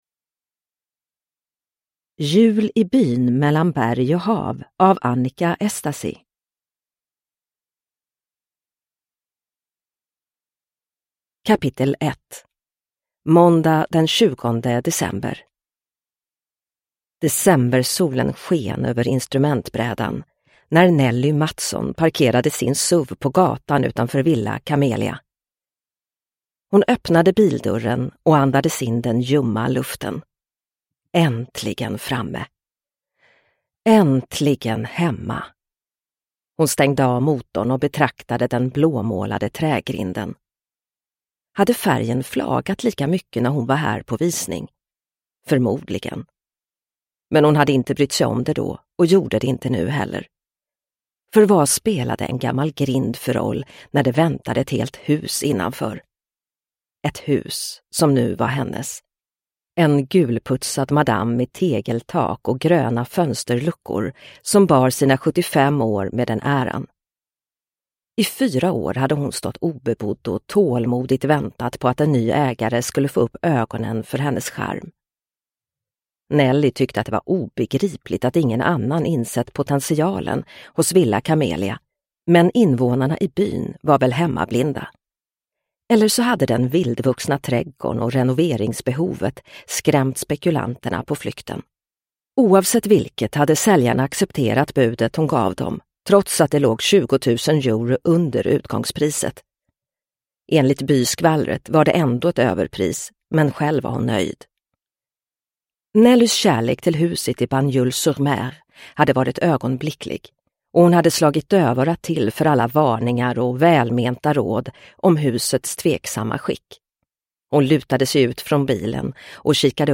Jul i byn mellan berg och hav – Ljudbok – Laddas ner